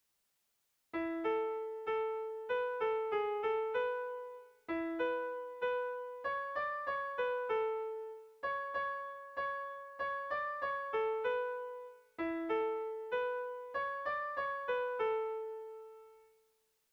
Gabonetakoa
ABDB